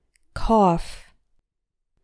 4. Pronounced like “off”